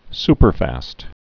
(spər-făst)